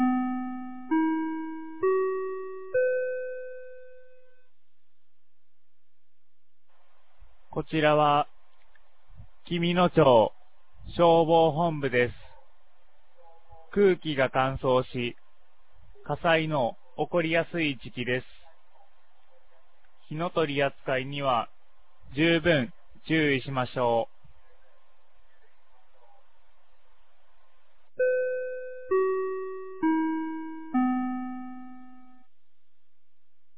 2023年11月25日 16時00分に、紀美野町より全地区へ放送がありました。